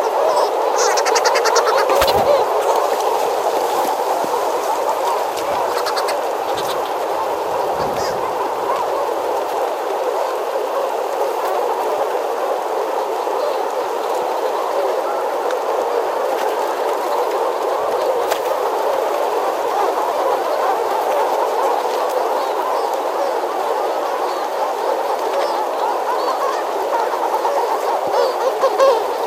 Halobaena caerulea - Petrel azulado
Petrel azulado.wav